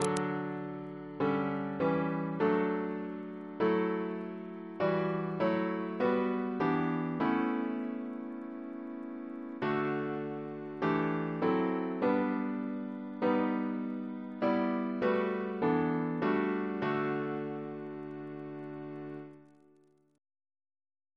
Double chant in D Composer